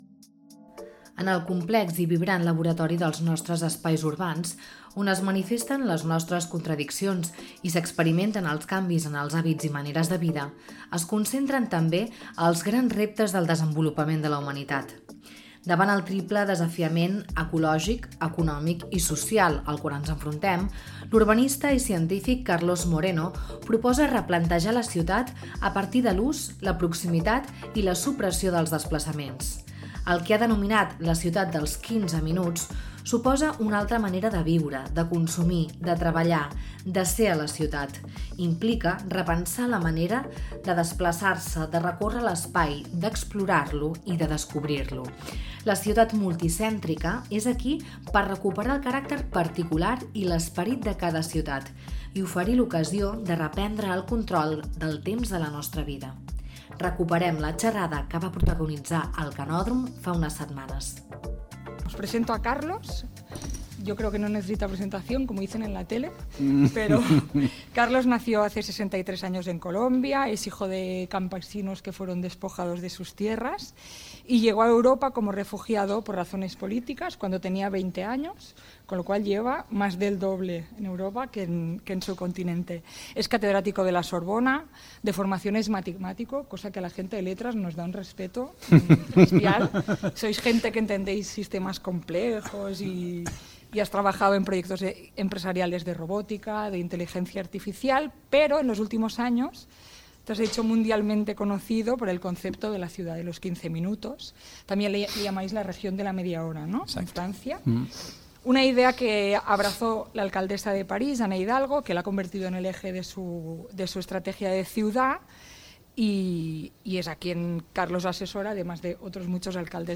La ciutat dels 15 minuts – Conversa amb Carlos Moreno